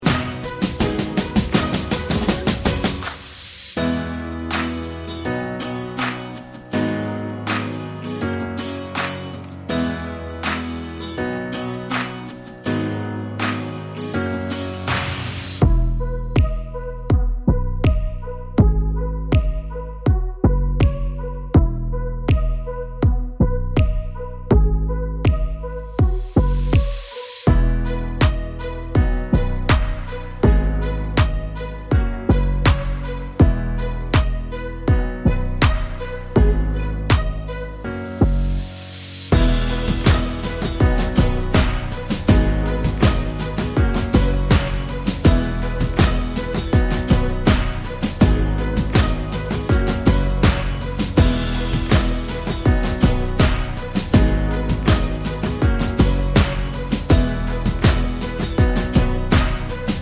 no Backing Vocals R'n'B / Hip Hop 3:46 Buy £1.50